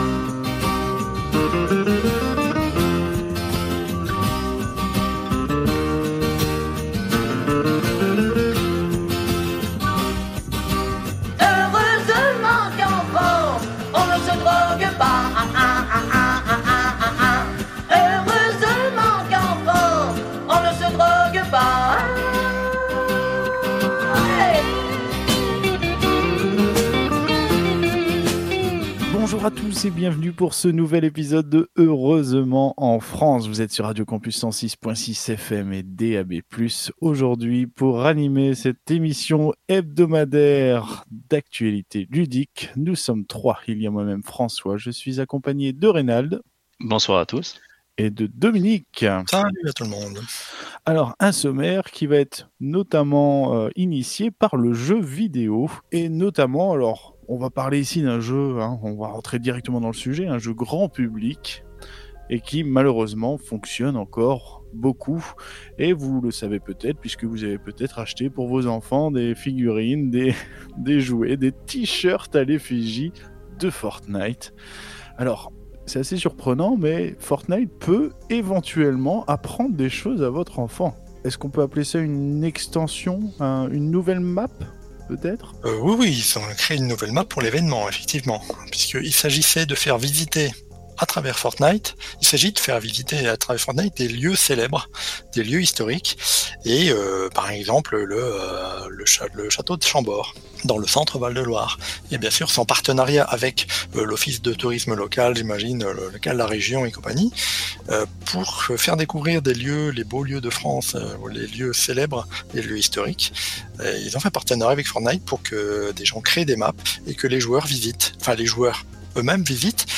Au sommaire de cet épisode diffusé le 20 décembre 2020 sur Radio Campus 106.6 :